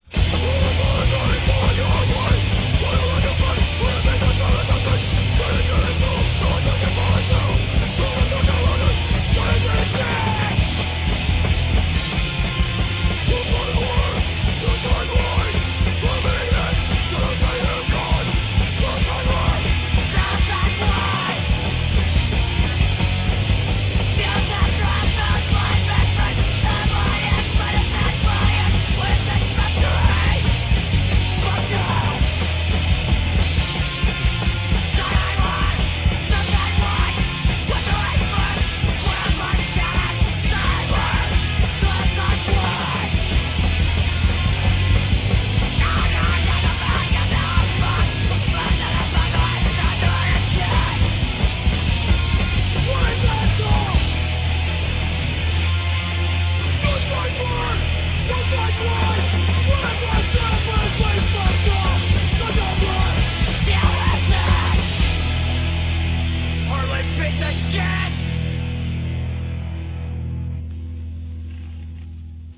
Out of Print - More Crust/HC from Seattle.